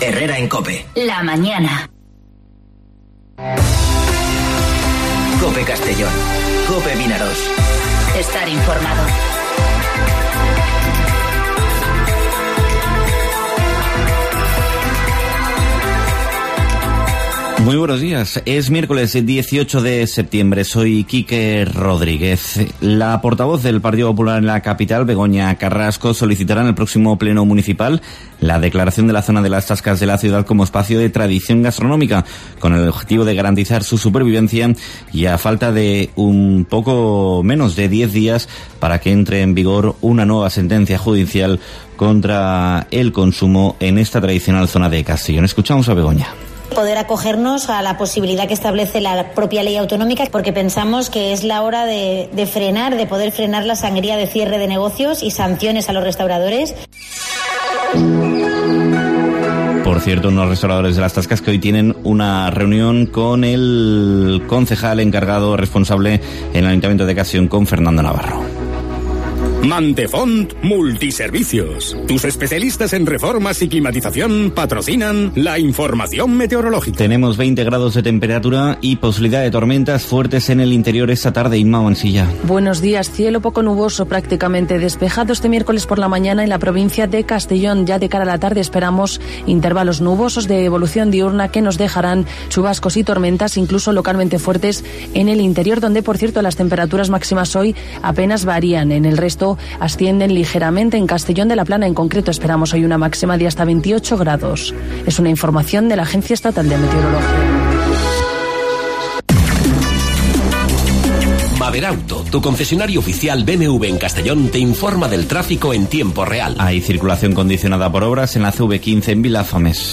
Informativo Herrera en COPE Castellón (18/09/2019)